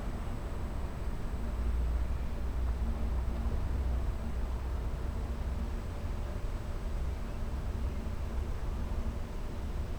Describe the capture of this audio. urban